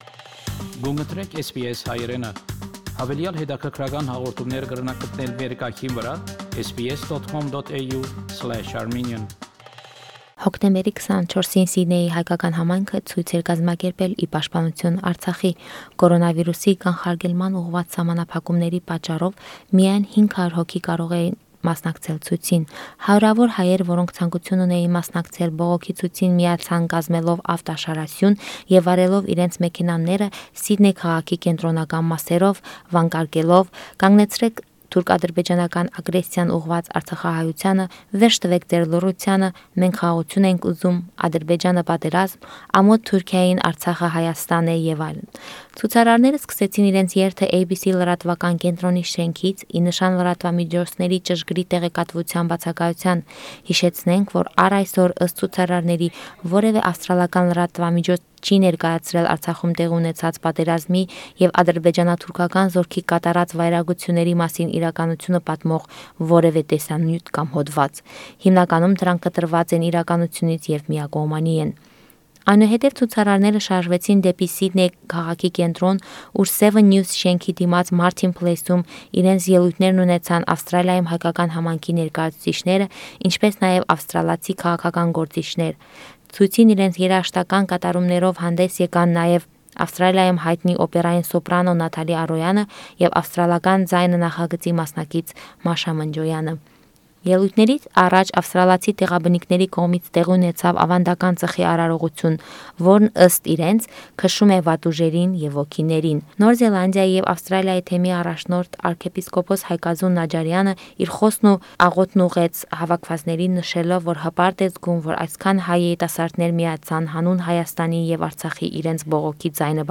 Special report on March for Armenians in Sydney.